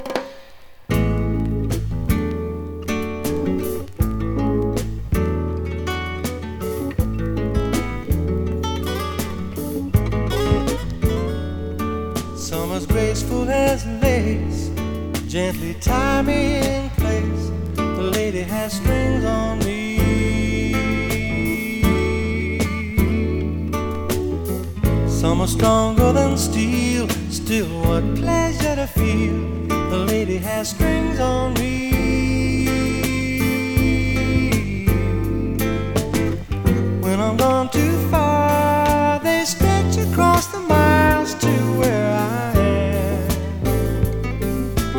以降、変わること無く、真摯な印象で、甘く伸びやかなヴォーカルが魅力です。
時代を感じさせるポップスやロックに、洗練されたストリングスアレンジも冴える、香り立つような良盤です。
Rock, Pop, Jazz, Folk　USA　12inchレコード　33rpm　Stereo